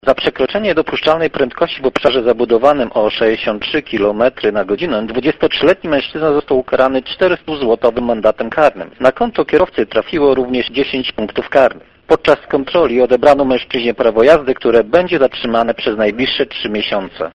O konsekwencjach takiego zachowania mówi